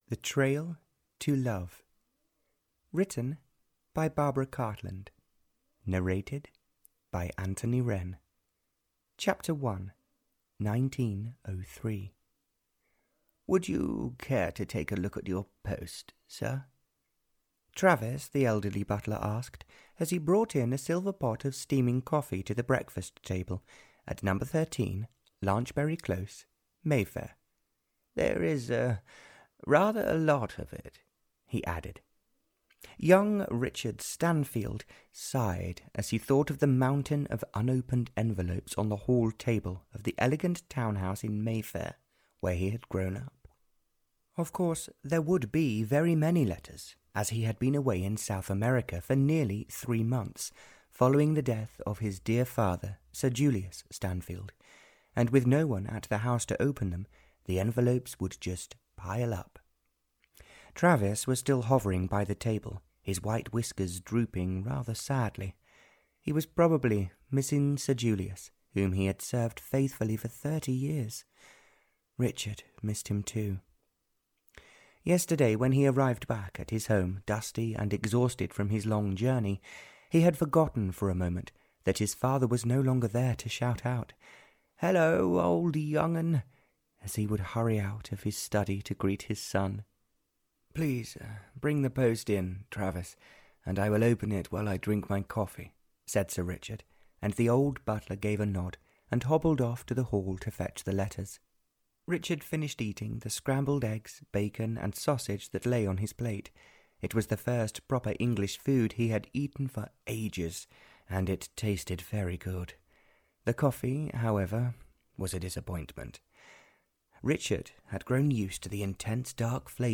Audio knihaThe Trail to Love (Barbara Cartland s Pink Collection 82) (EN)
Ukázka z knihy